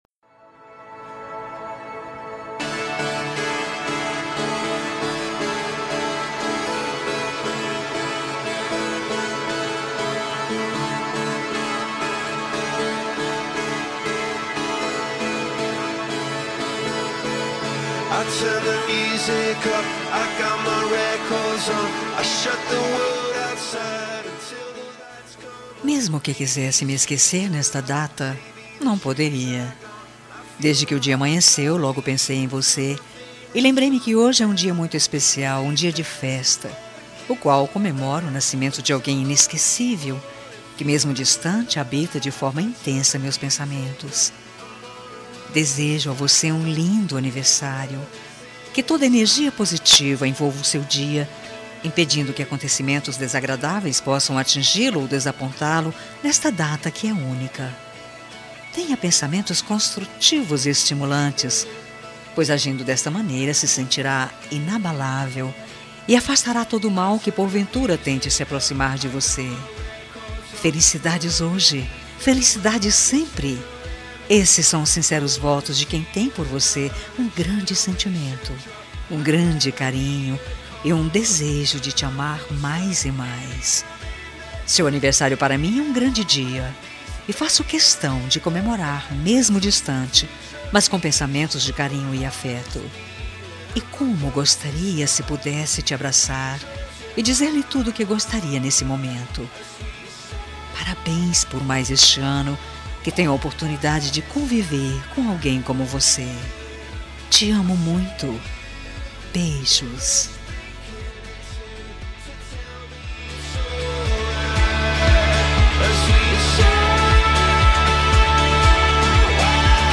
Telemensagem de Aniversário Romântico – Voz Feminina – Cód: 202043 – Distante